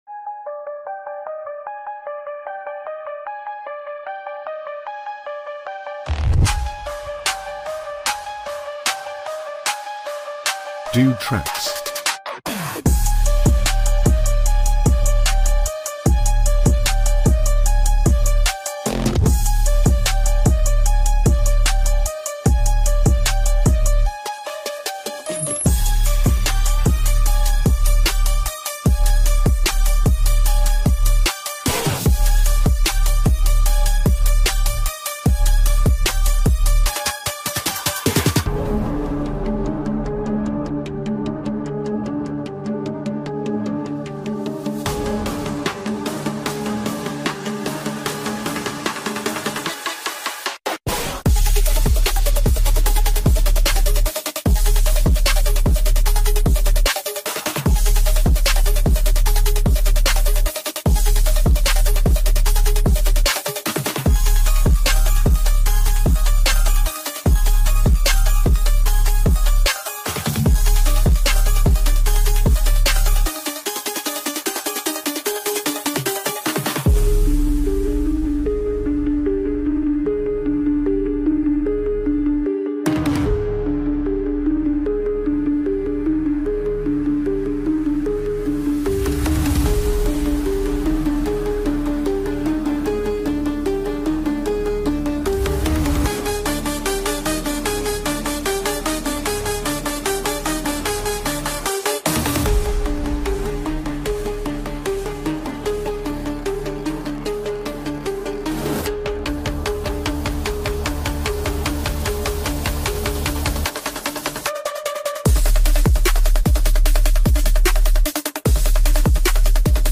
Royalty-Free Hip Hop Beat
epic no copyright music beat